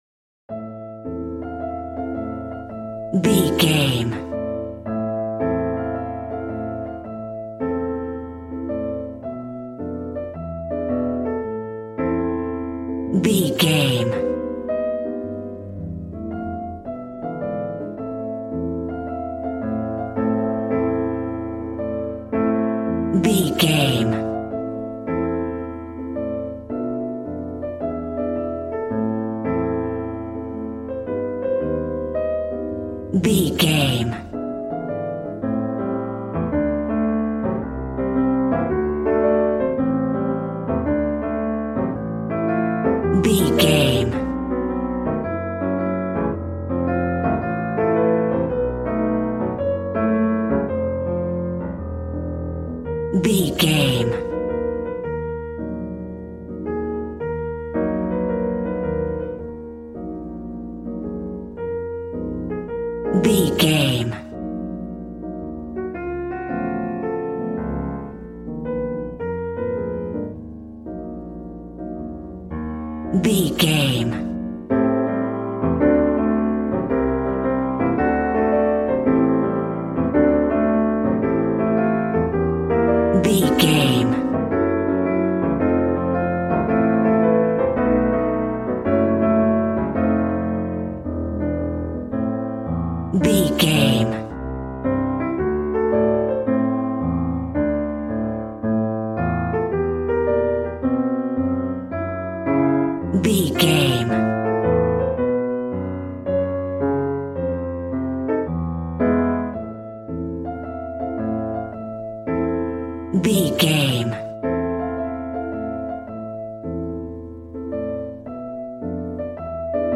Aeolian/Minor
sexy
smooth
piano
drums